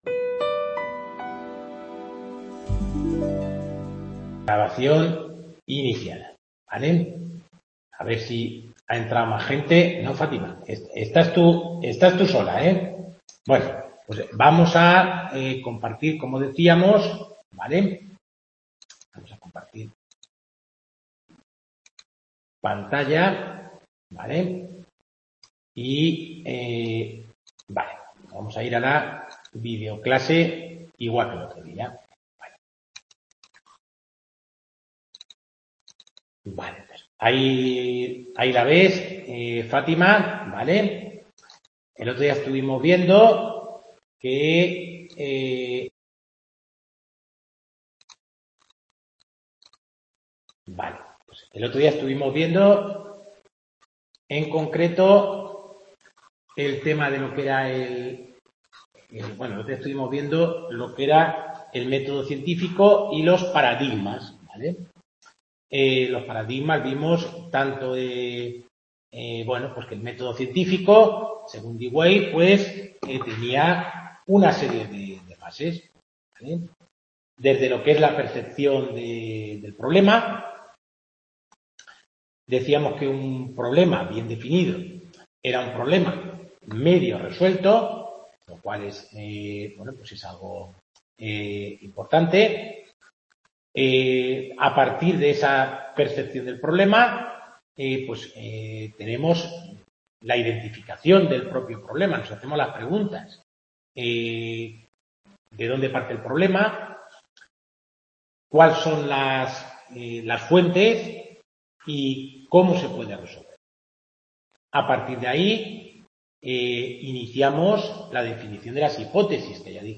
Tutoría Centro Asociado Guadalajara Métodos Investigación